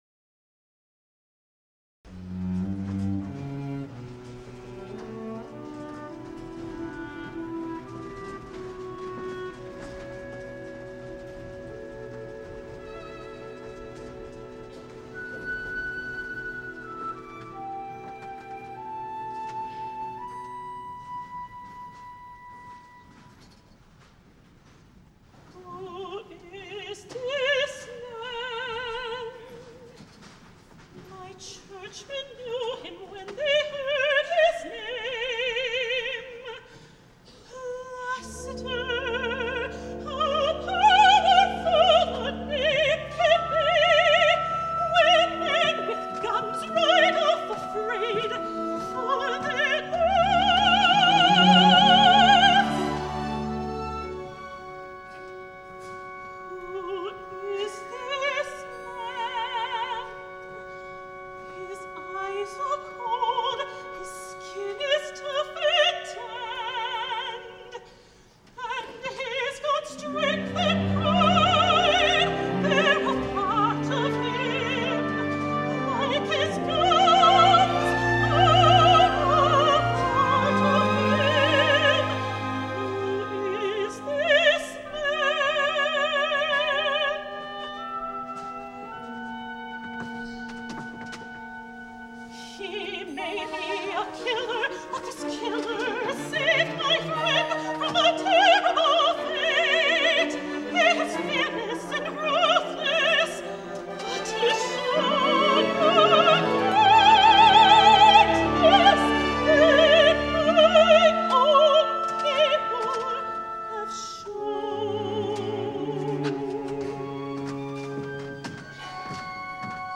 Voicing: Soprano Voice and Piano